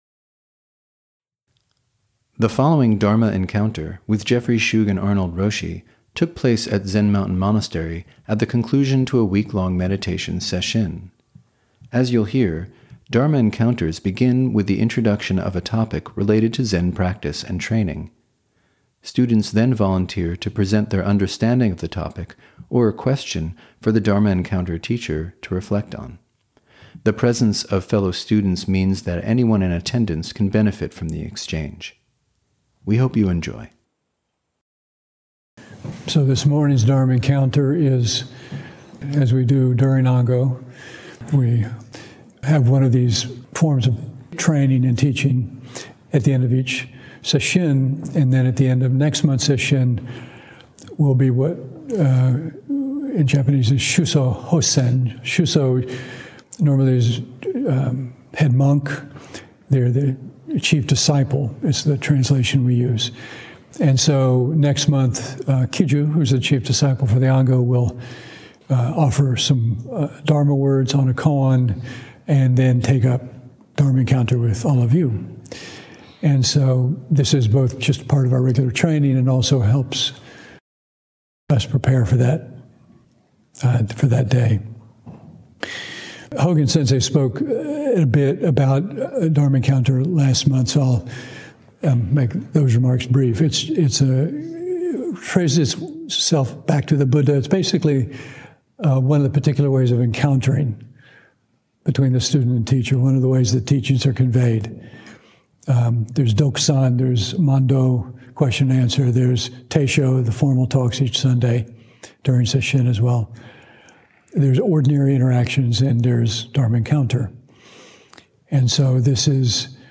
(Dharma Encounter at the conclusion of the October 2025 Harvest Sesshin.)